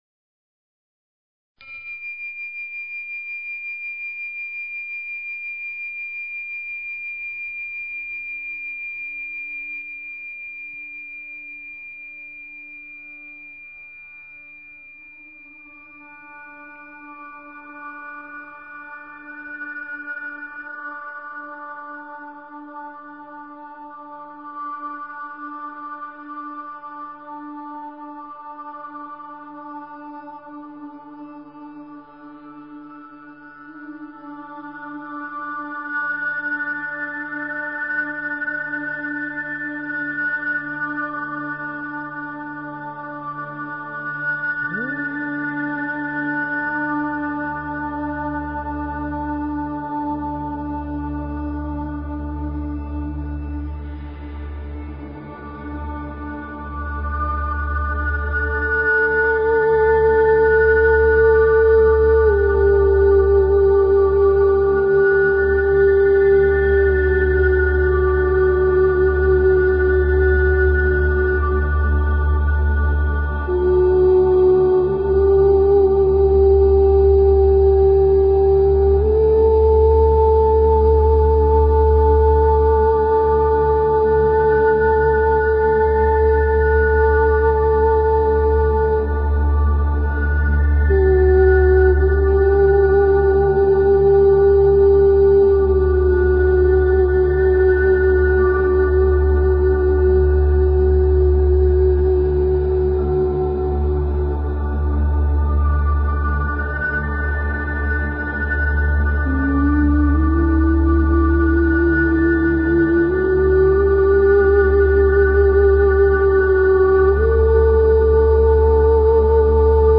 Talk Show Episode, Audio Podcast, Radiance_by_Design and Courtesy of BBS Radio on , show guests , about , categorized as
This is a call in show so call in!